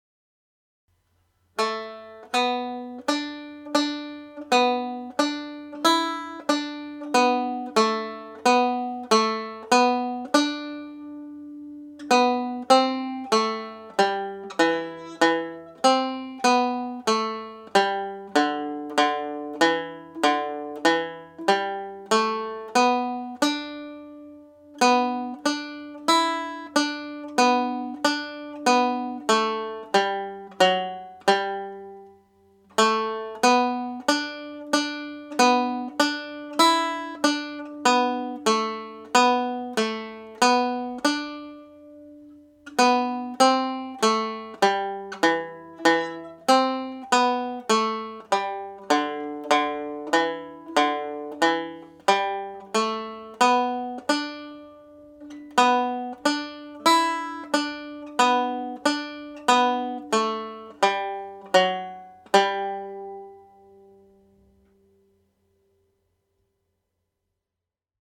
second part played slowly